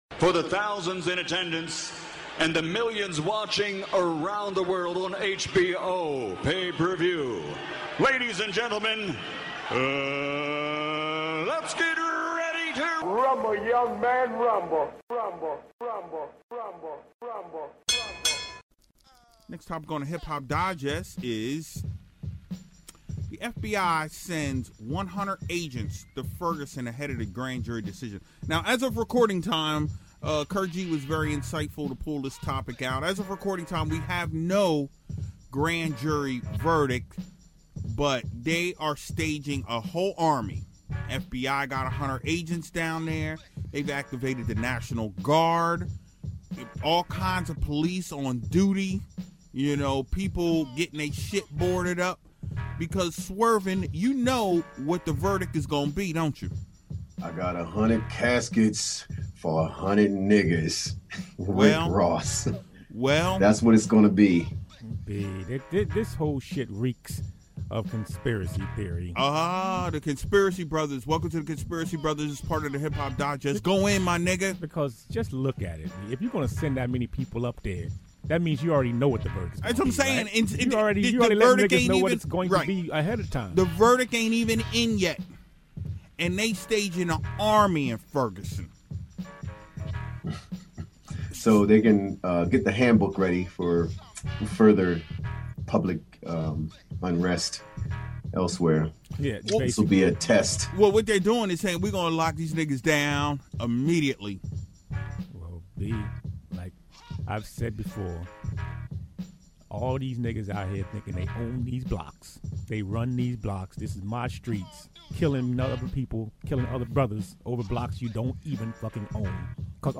What started out as a conversation on Ferguson turned into a full blown debate. Your boys really got into this one!